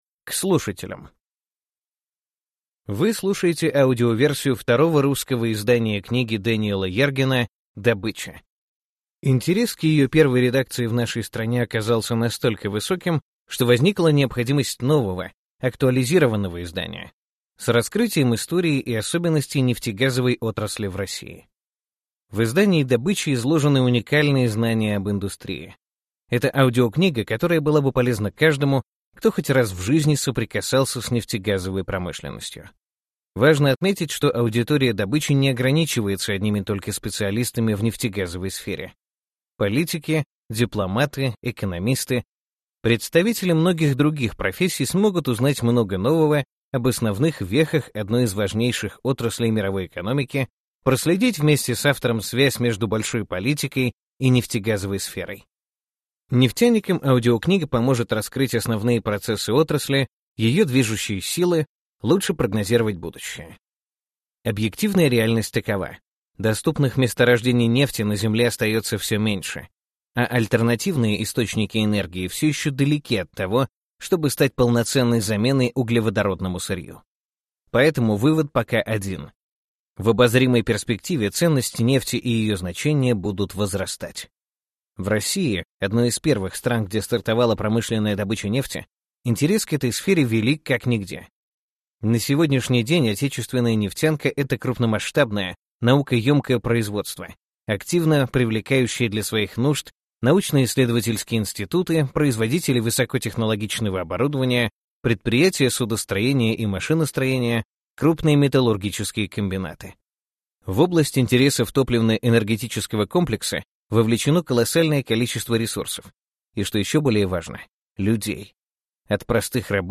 Аудиокнига Добыча: Всемирная история борьбы за нефть, деньги и власть | Библиотека аудиокниг